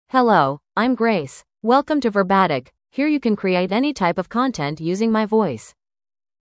FemaleEnglish (United States)
GraceFemale English AI voice
Grace is a female AI voice for English (United States).
Voice sample
Listen to Grace's female English voice.
Female
Grace delivers clear pronunciation with authentic United States English intonation, making your content sound professionally produced.